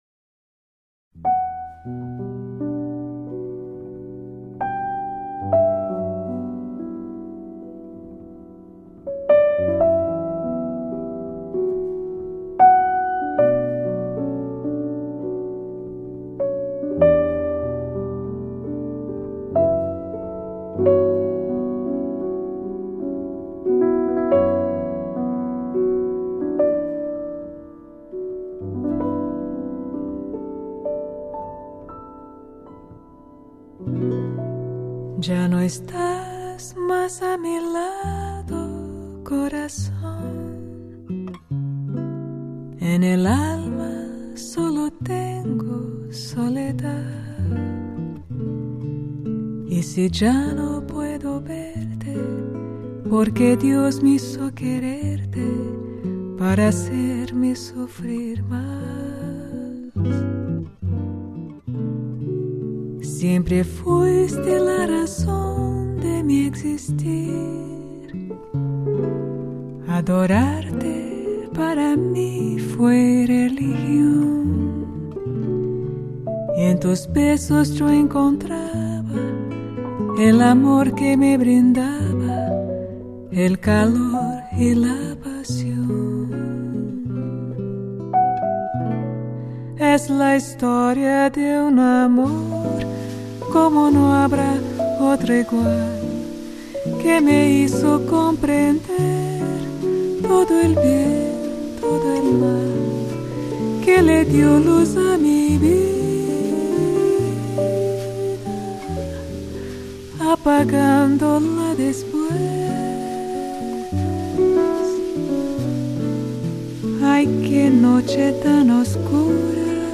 音樂類型：爵士樂